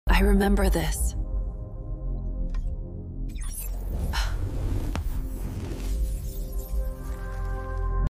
Hypnotic Futuristic ASMR ✨ Ultra Satisfying & Relaxing